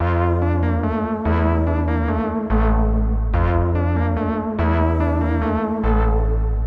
Tag: 144 bpm Trap Loops Synth Loops 1.12 MB wav Key : Unknown